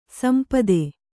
♪ sampade